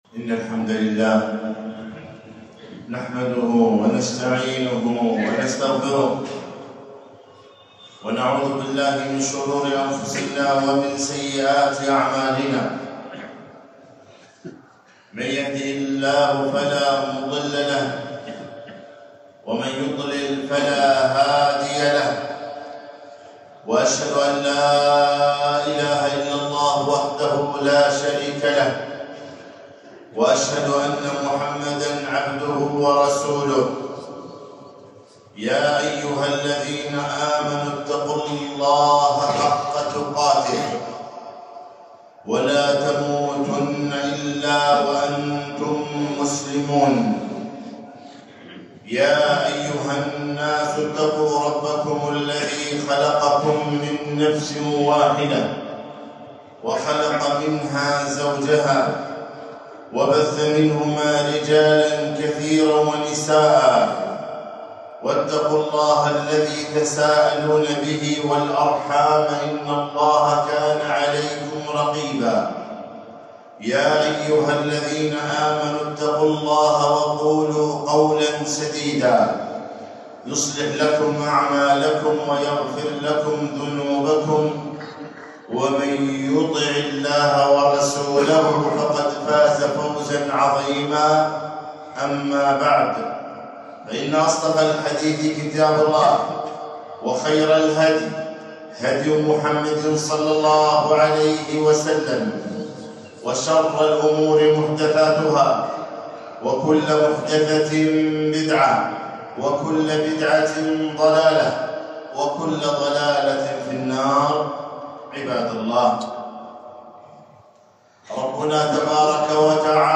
خطبة - الوالد أوسط أبواب الجنة